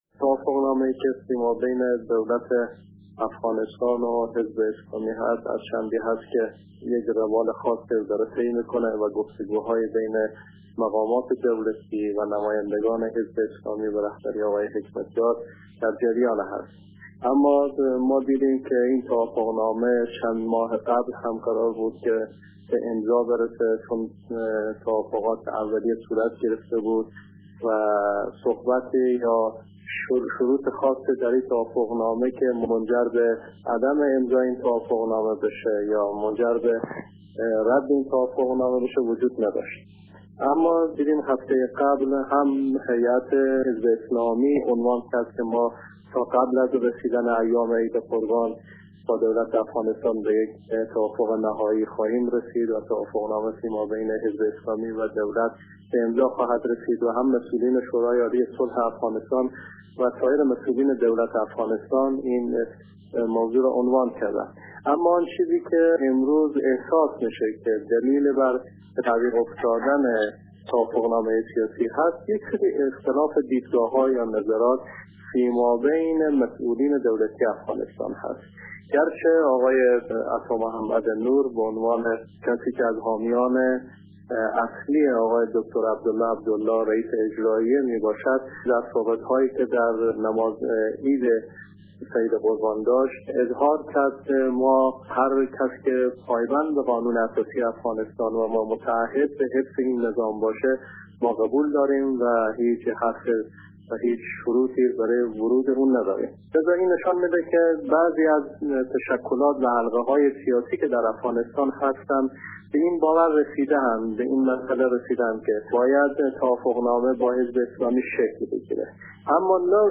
در مصاحبه با رادیو دری